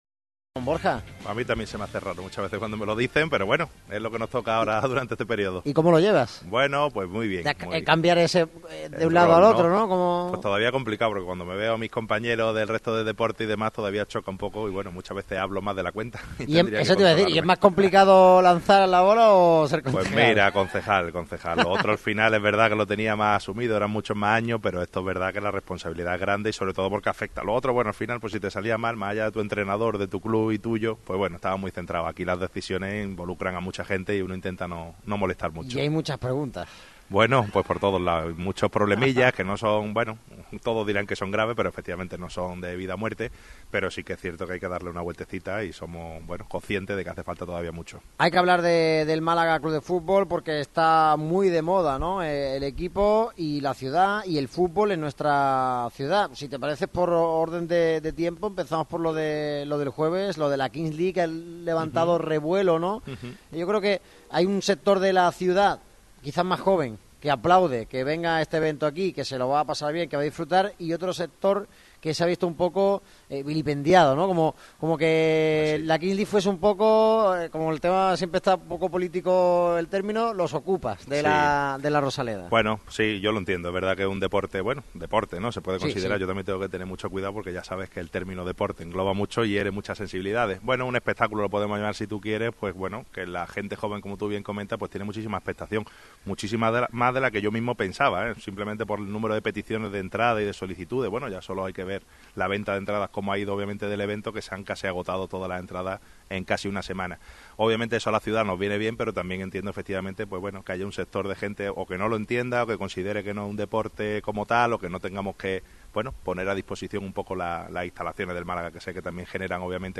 Este lunes ha pasado por el micrófono rojo desde Cervezas Victoria Borja Vivas. El atleta español y actual concejal de Deportes fue cuestionado por la Kings League y el papel de Málaga para el Mundial 2030 entre otras cuestiones. El Ayuntamiento se compromete a reformar La Rosaleda aunque no sea sede.